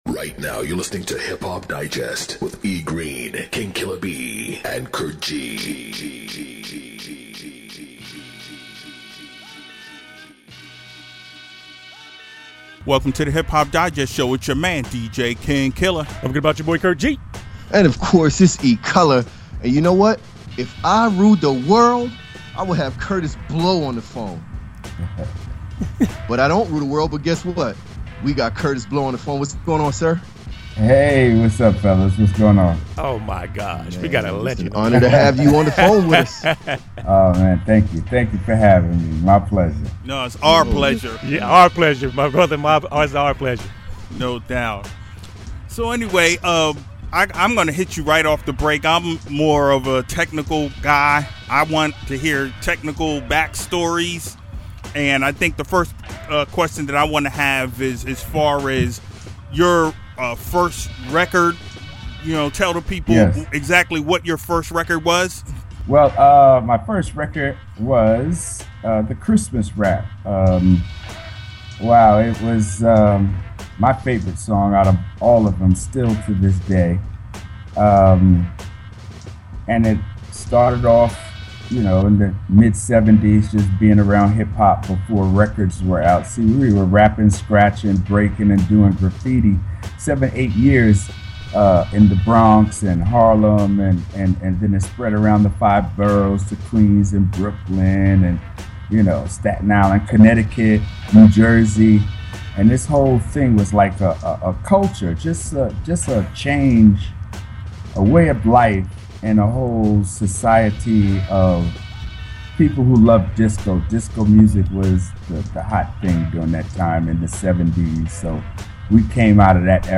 Hip-Hop Digest Show – Kurtis Blow Interview
Legendary interview of a legendary MC. The one and only Kurtis Blow stopped thru to drop some GEMS!!!!